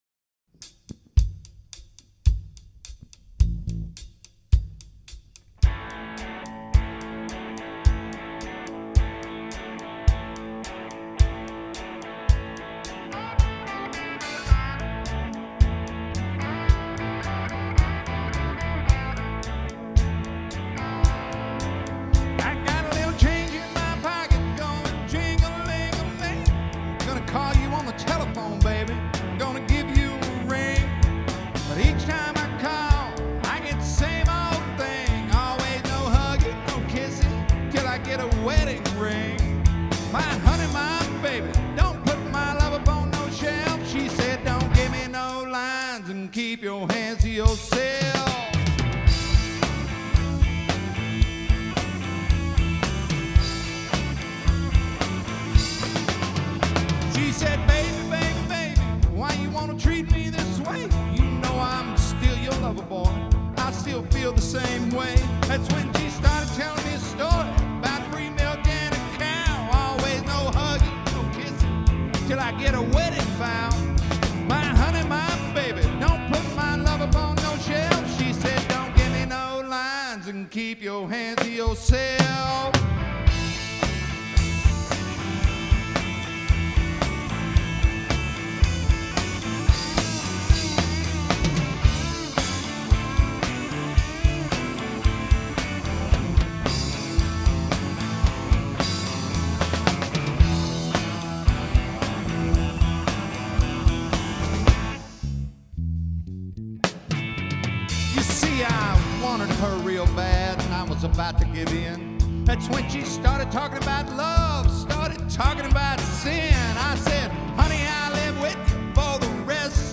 Here are a couple of songs we played at a recent wedding.
live, at Nutty Brown
classic Texas country music, plus a little rock and/or roll